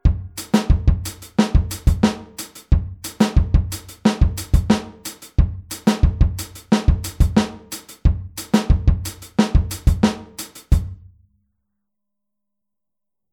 Hier spielen wir den Offbeat mit der rechten Hand wieder auf dem HiHat.